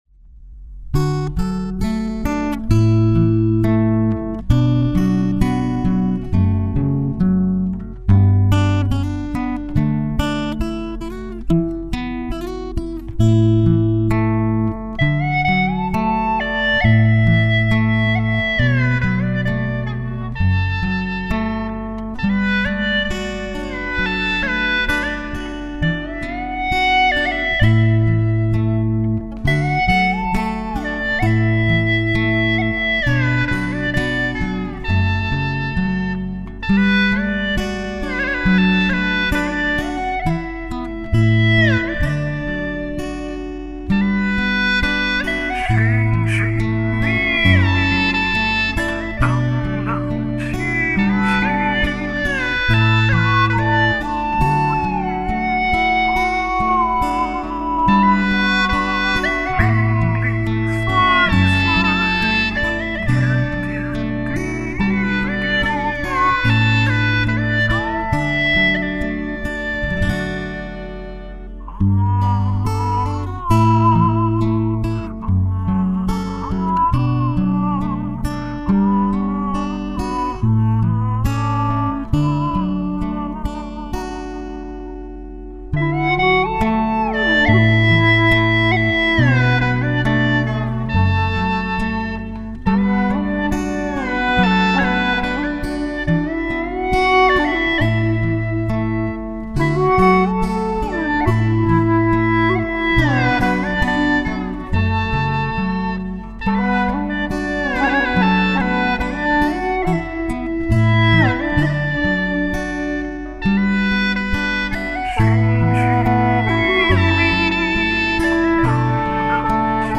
调式 : D 曲类 : 流行